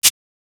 LM-1_SHAKER_1_TL.wav